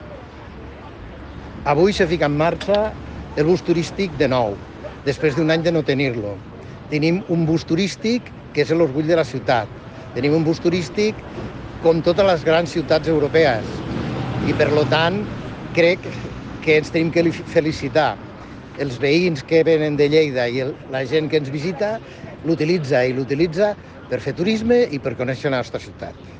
tall-de-veu-del-tinent-dalcalde-paco-cerda-sobre-la-posada-en-marxa-aquest-estiu-del-bus-turistic-de-lleida